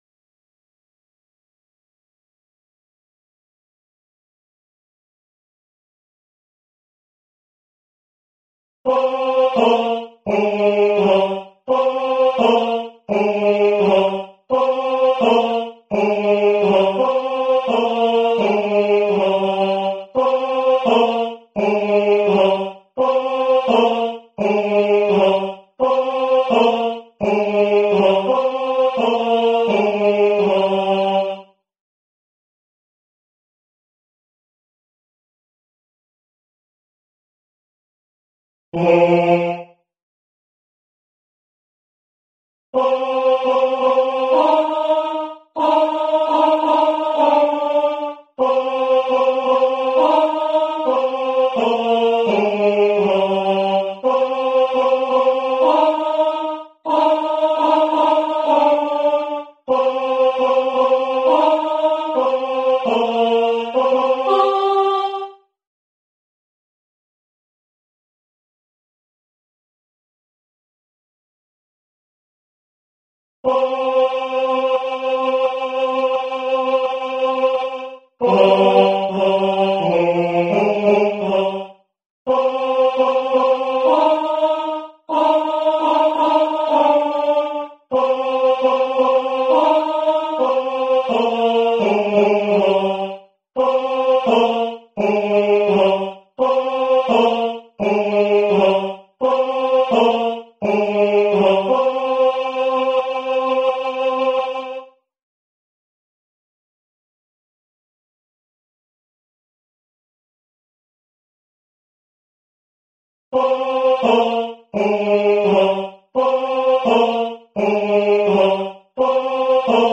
Coro musica afroamericana, blues, swing, spirituals - Bologna